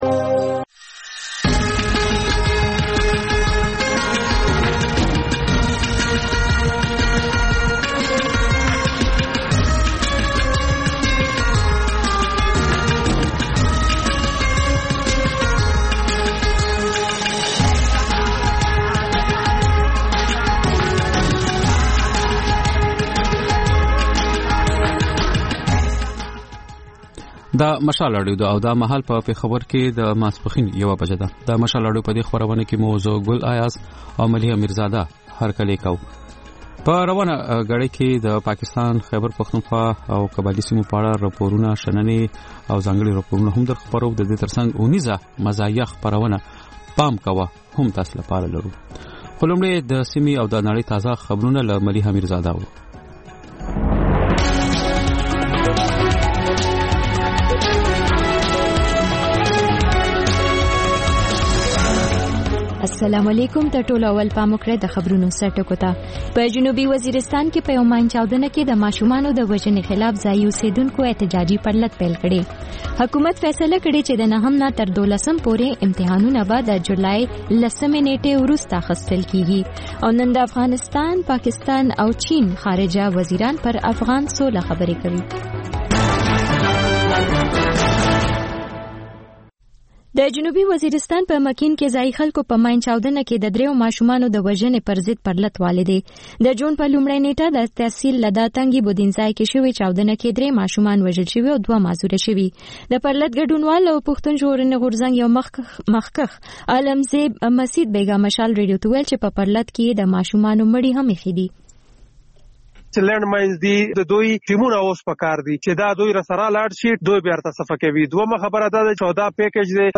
د مشال راډیو لومړۍ ماسپښينۍ خپرونه. په دې خپرونه کې تر خبرونو وروسته بېلا بېل رپورټونه، شننې، مرکې خپرېږي. ورسره اوونیزه خپرونه/خپرونې هم خپرېږي.